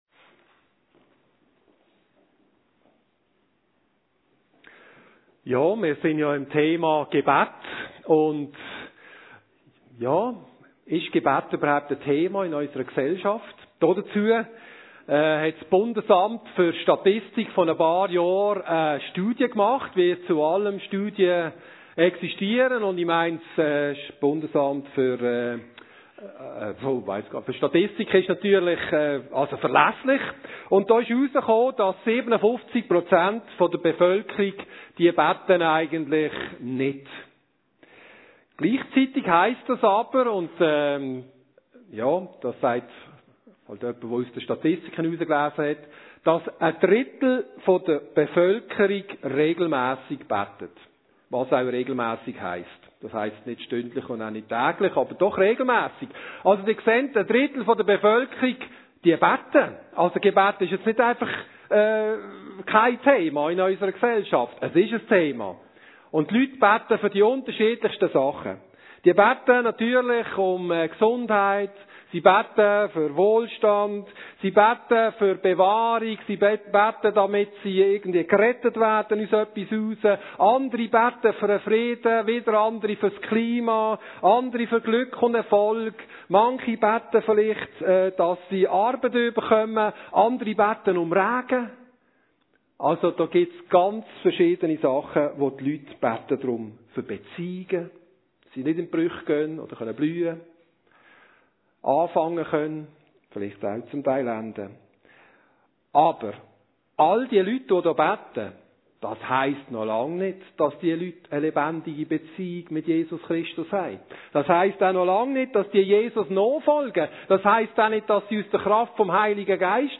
Predigende